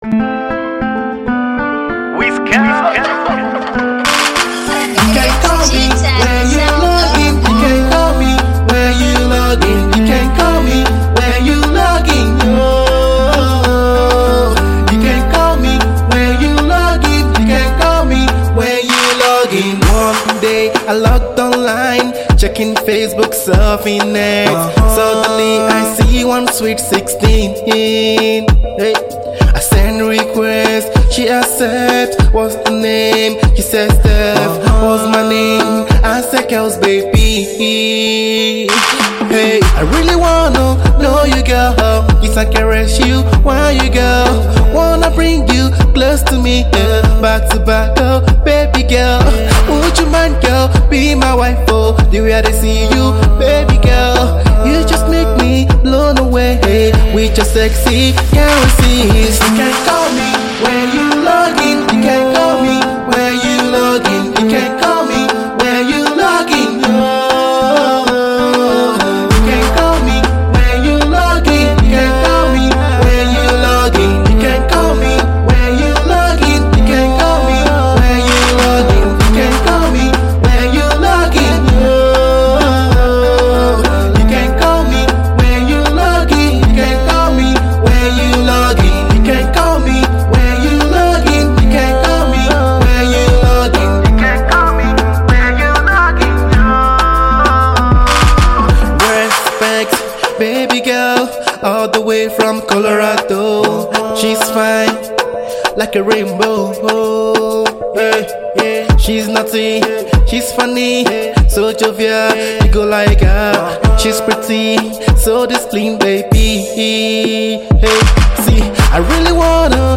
Pop Africa